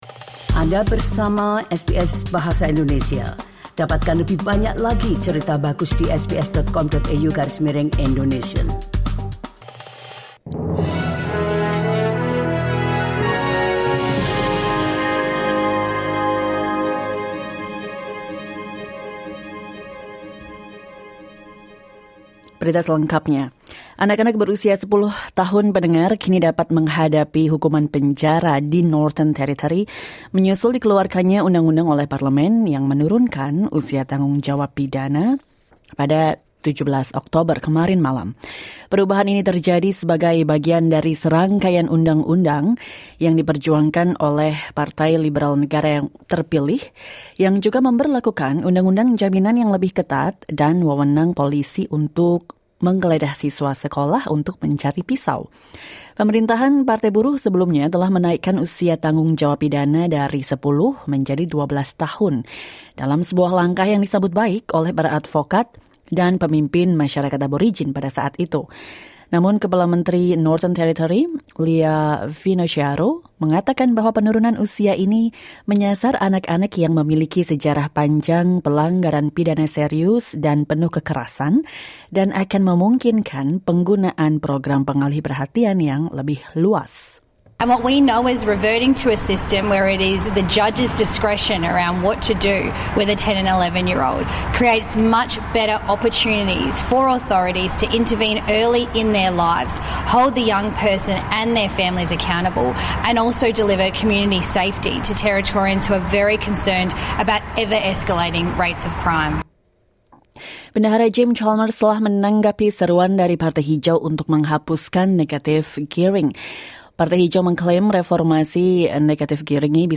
SBS Audio news in Indonesian - 18 October 2024